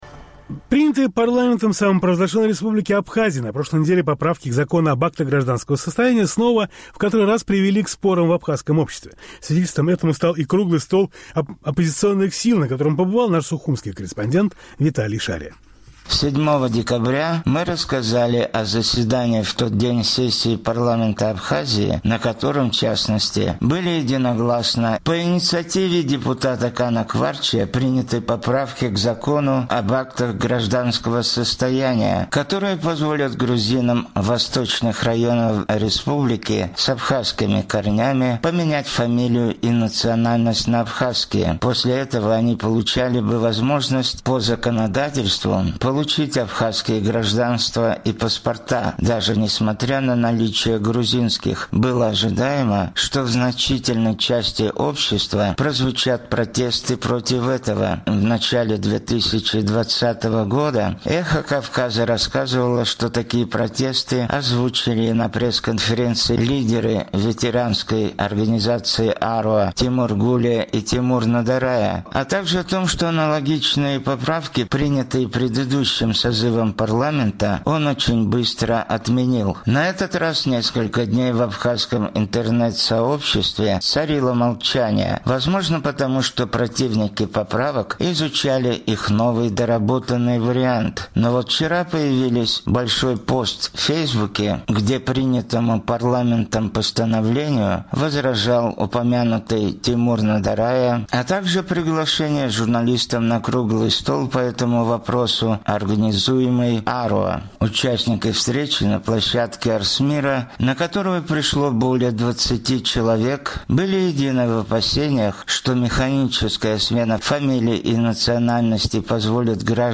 Участники встречи на площадке АРСМИРА, на которую пришло более двадцати человек, были едины в опасениях, что механическая смена фамилий и национальности позволит гражданам Грузии, проживающим в Гальском районе в старых границах, массово получать абхазское гражданство и создаст возможность превращения их в пятую колонну в абхазском этносе.